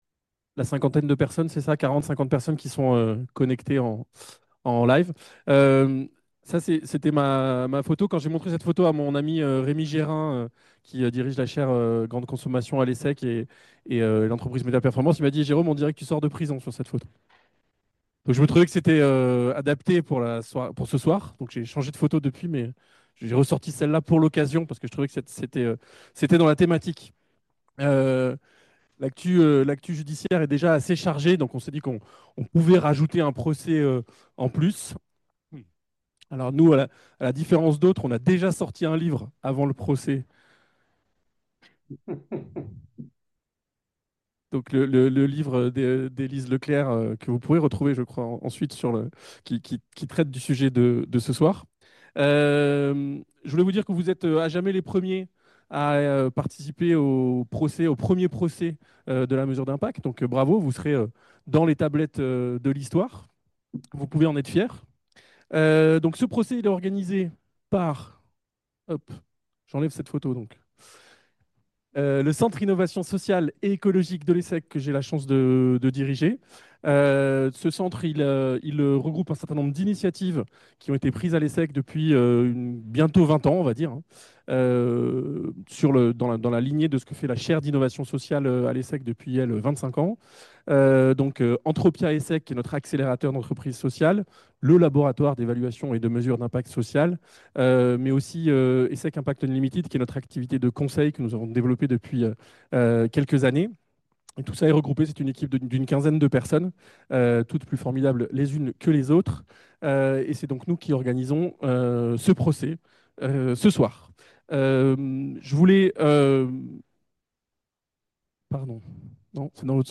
Parfois présentée comme une évidence, l’évaluation d’impact social suscite aussi certaines interrogations : Passage obligé pour satisfaire les financeurs ? Pertinence et fiabilité des méthodes ? Coûts et effets pervers ? Le CISE s’est penché sur ces questions au cours d’une soirée inédite , qui a pris la forme d’un procès de l’évaluation d’impact : accusations, plaidoiries, témoins et jury ont permis d’examiner la pratique sous toutes ses coutures. Une soirée instructive, ludique, sur un fond d’humour, pour aborder un sujet crucial.